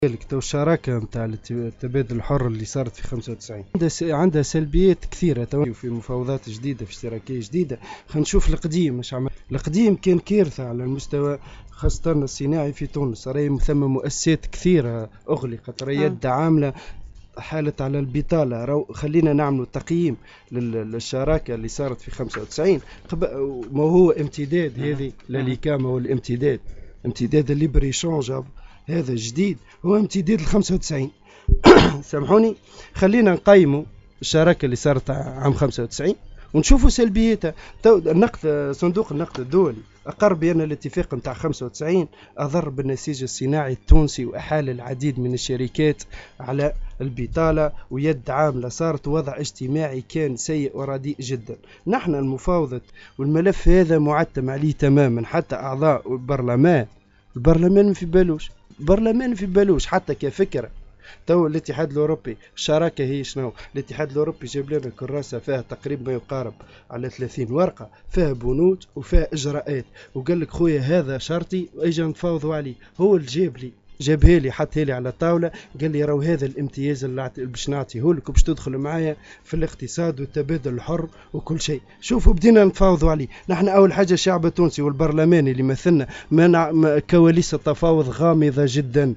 برنامج حديث قصارنية على موجات السيليوم أف أم